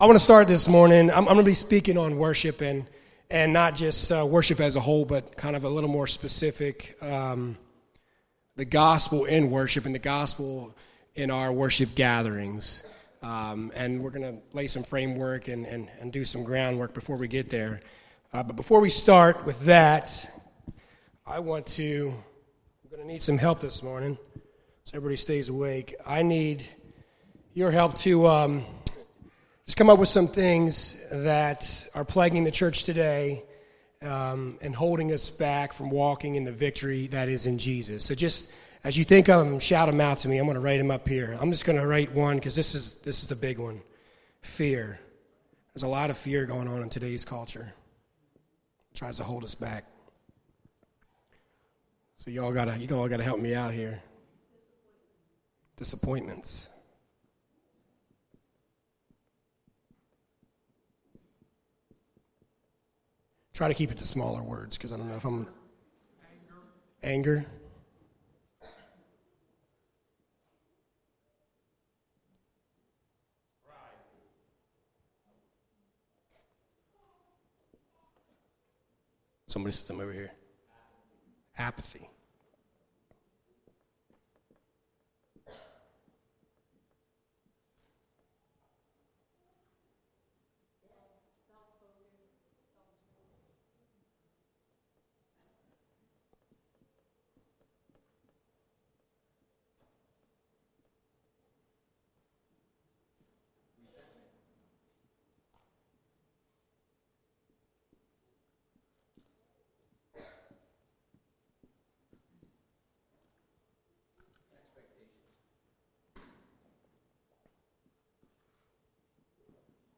Maranatha Fellowship's Sunday Morning sermon recordings.